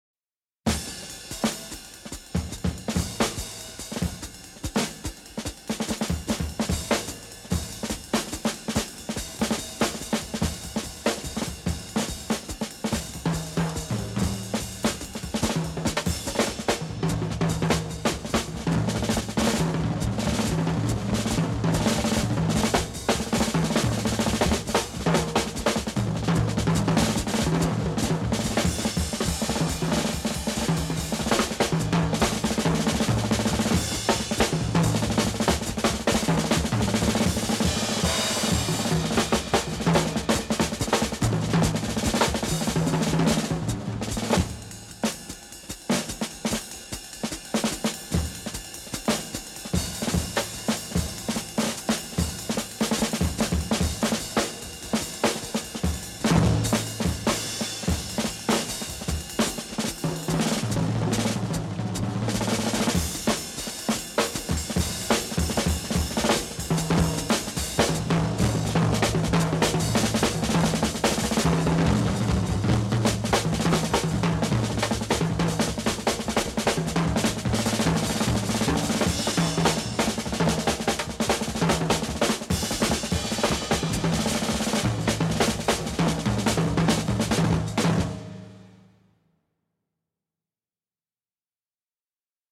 Pas de thème marquant, plutôt des ambiances.